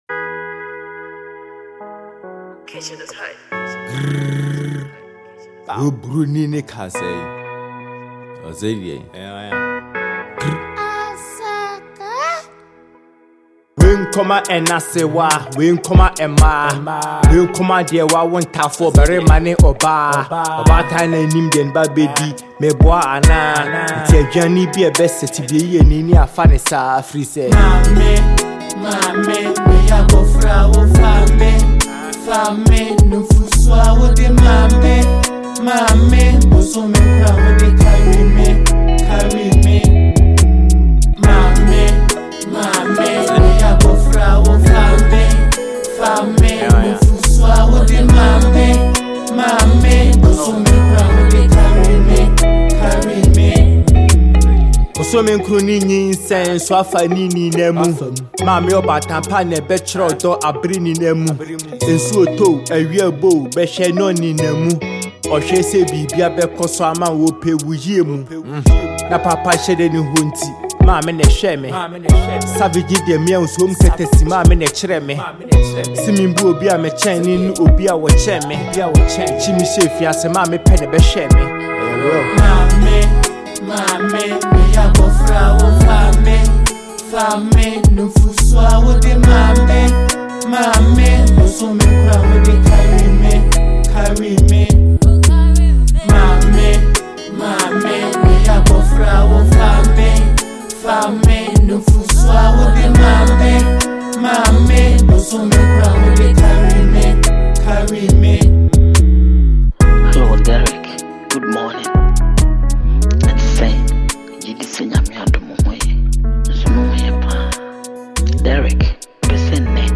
Kumerican Rapper